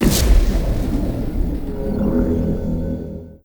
Explosion2.wav